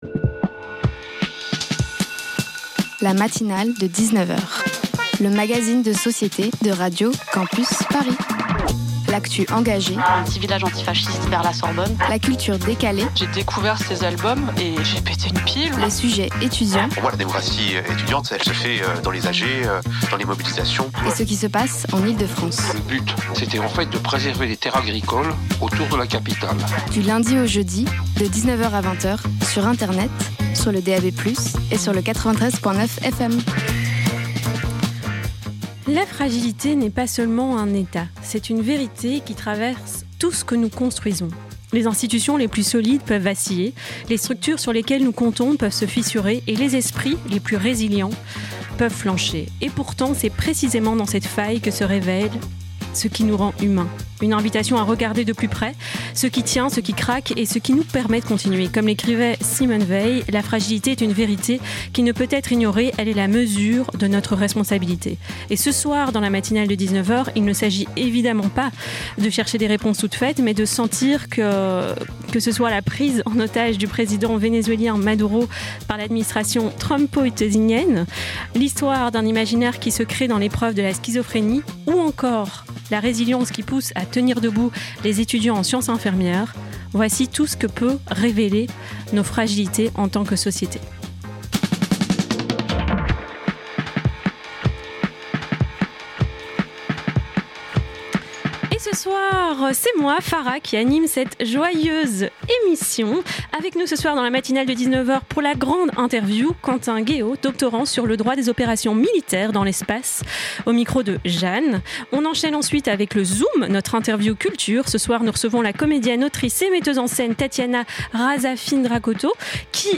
Légalité de l'enlèvement de Maduro & la pièce Les voix de l'armoire Partager Type Magazine Société Culture mercredi 21 janvier 2026 Lire Pause Télécharger Ce soir